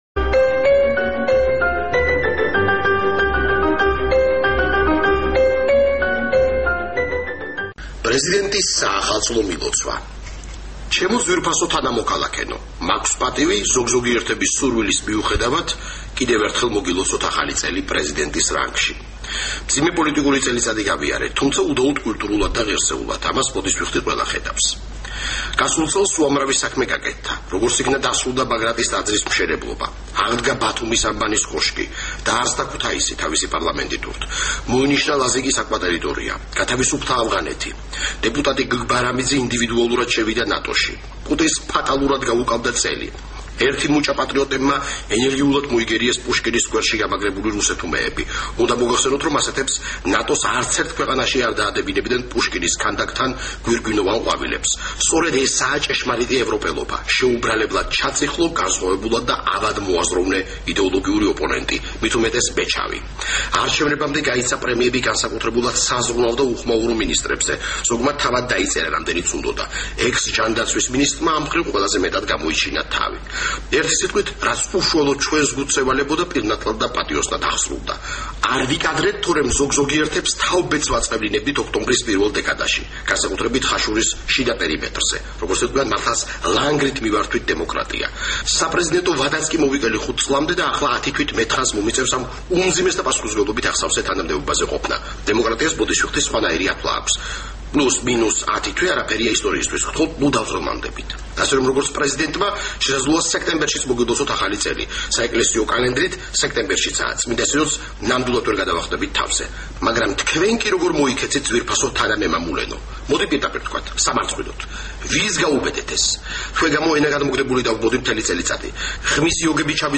პრეზიდენტის საახალწლო მილოცვა